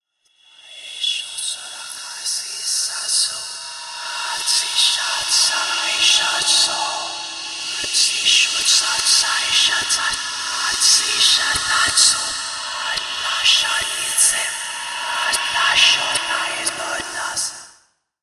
Звуки страшного голоса
Голоса что можно услышать в заброшенном замке